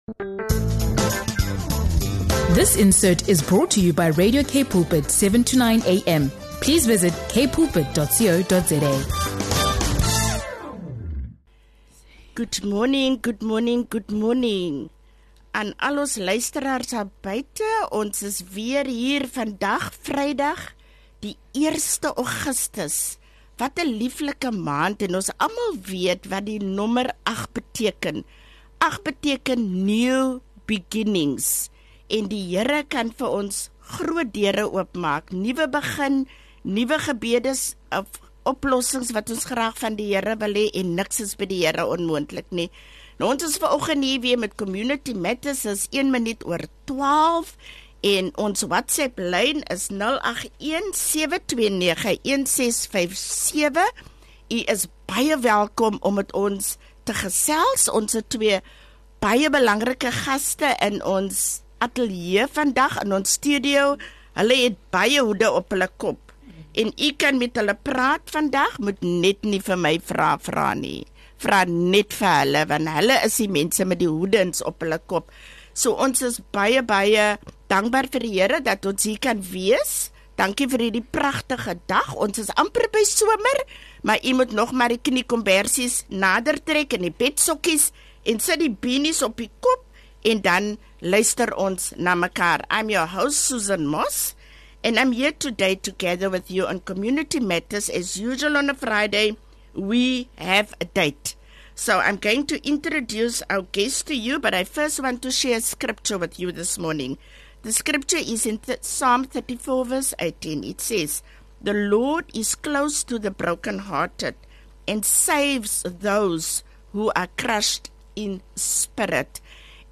Tune in for a heartfelt conversation filled with wisdom, compassion, and the joy of walking in the rain.